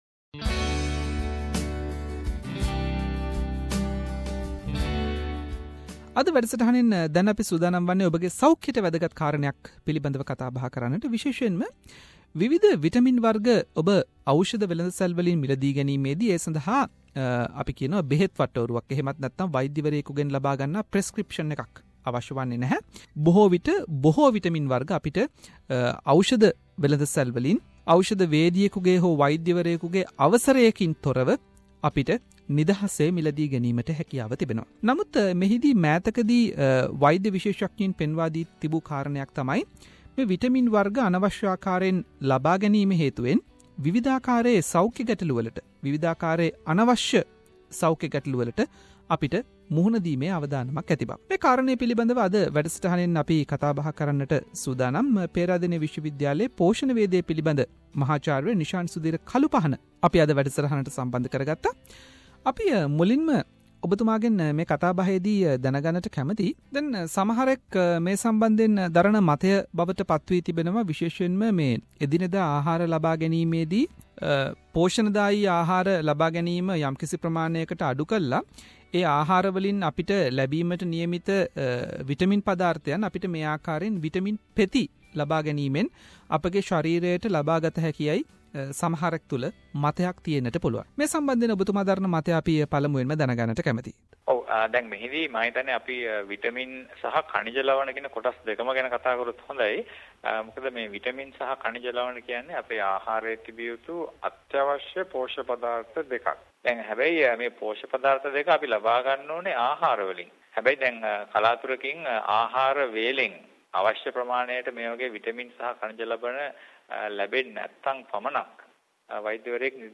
SBS සිංහල වැඩසටහන කල කතා බහක්.